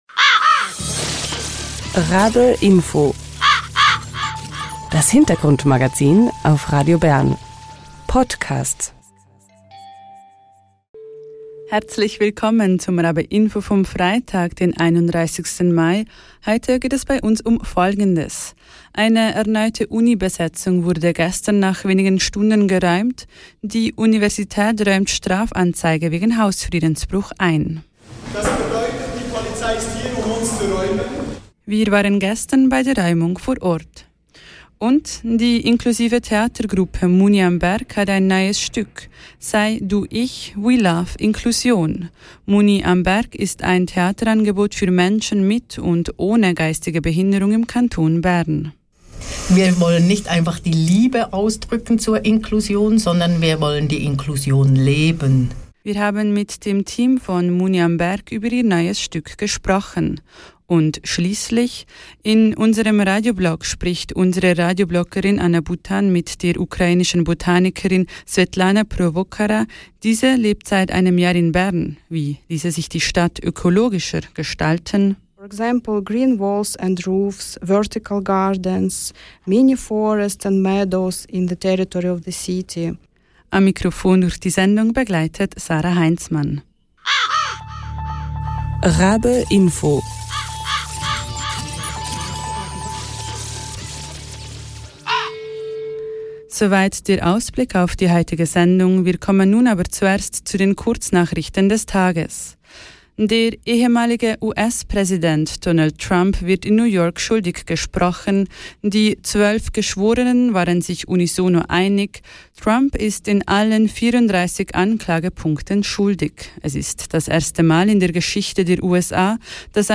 Wir haben mit dem Team von Muni am Bärg über ihr neues Stück gesprochen.